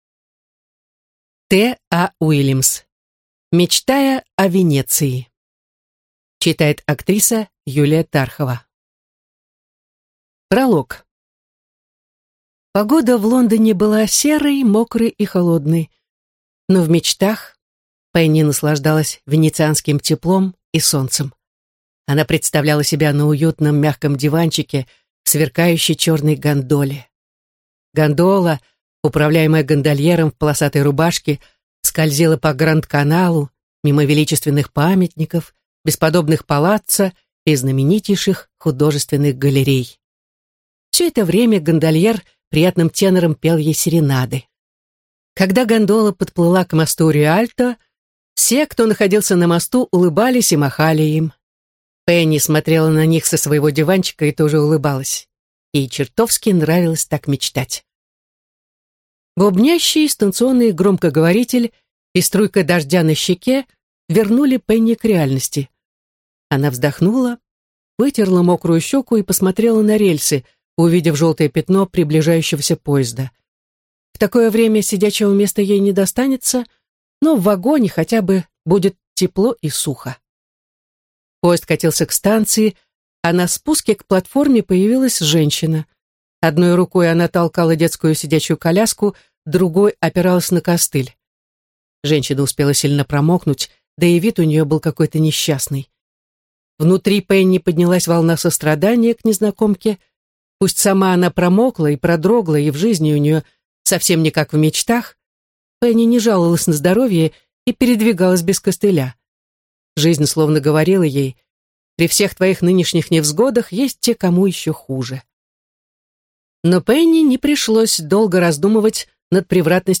Аудиокнига Мечтая о Венеции | Библиотека аудиокниг